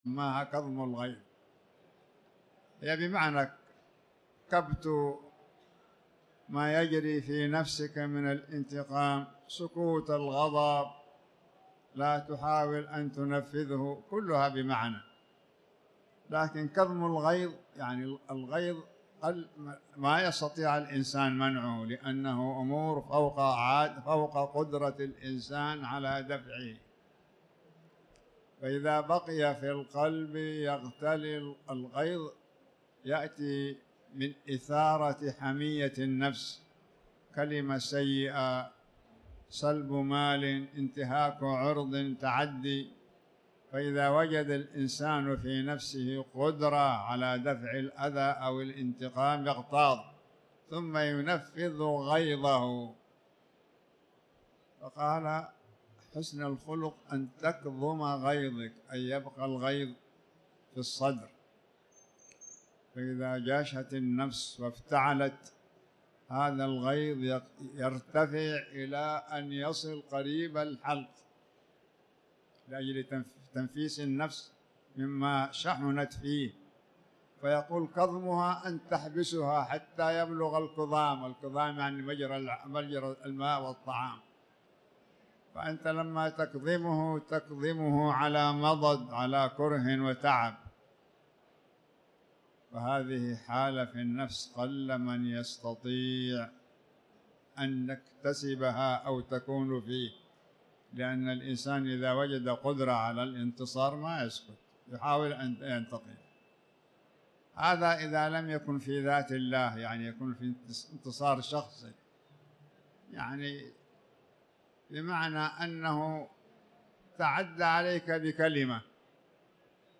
تاريخ النشر ٢١ جمادى الآخرة ١٤٤٠ هـ المكان: المسجد الحرام الشيخ